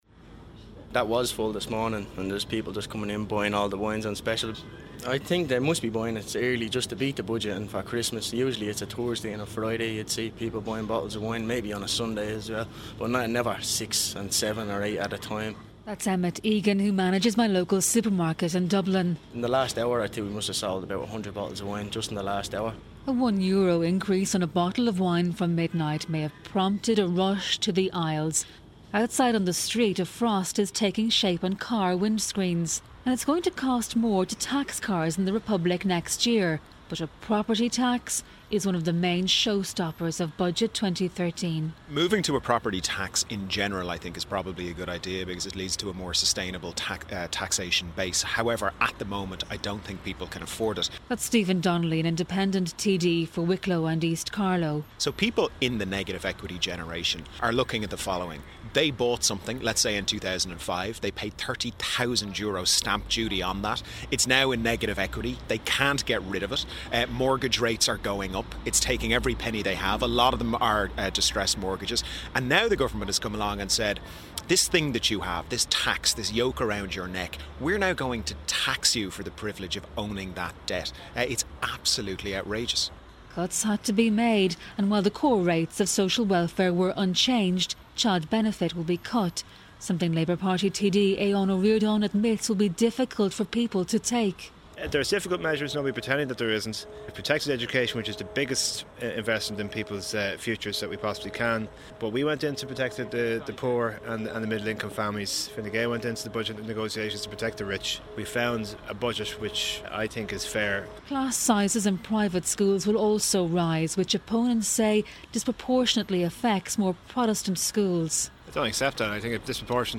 (Broadcast BBC Good Morning Ulster Dec 6)